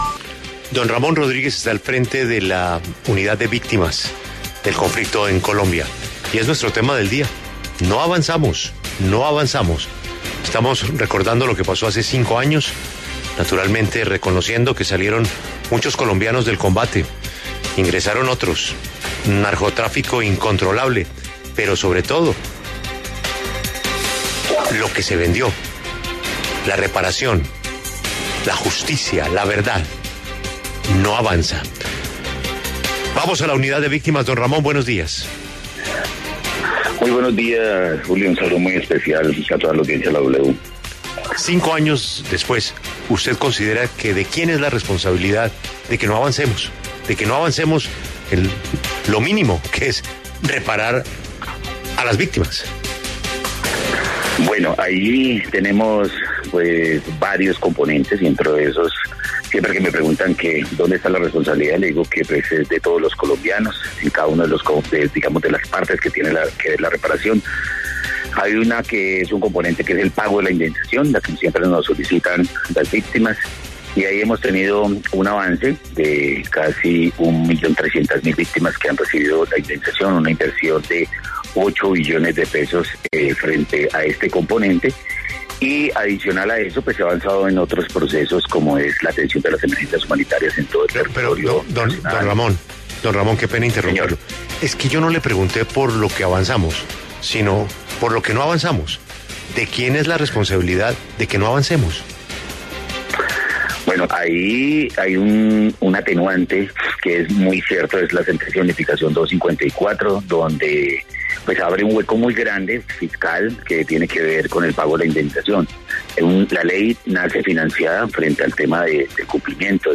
En entrevista con La W, el director de la Unidad de Víctimas Ramón Rodríguez se refirió (a propósito de los cinco años del Acuerdo de Paz) a la reparación por parte de la extinta guerrilla de las Farc a las víctimas, y señaló que desde los reincorporados no han recibido absolutamente nada de recursos luego del Acuerdo.